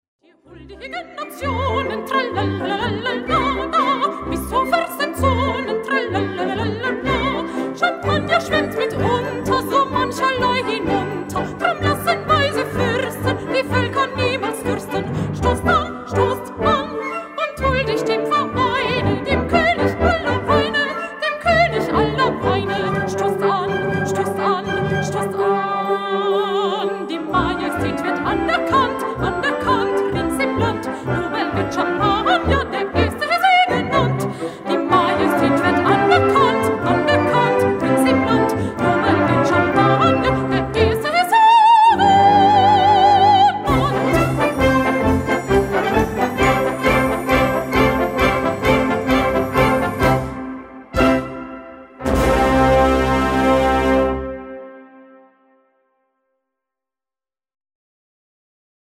Gattung: Zwei Arien für Sopran und Blasorchester
Besetzung: Blasorchester